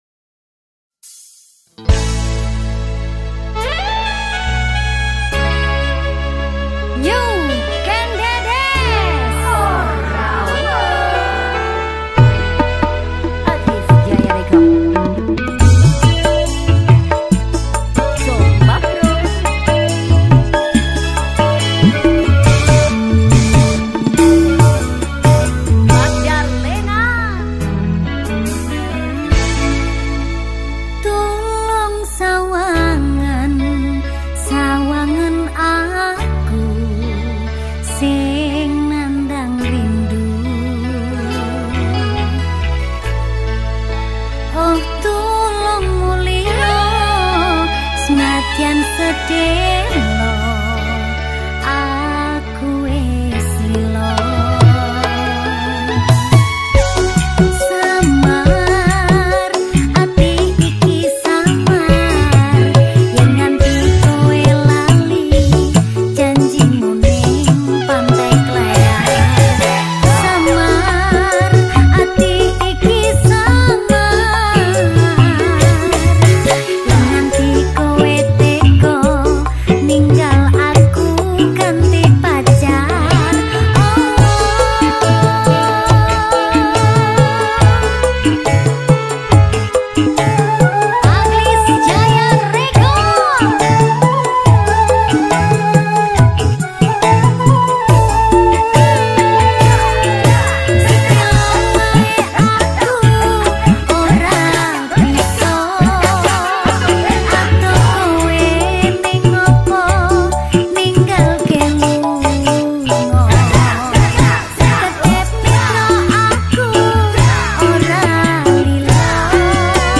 Dangdut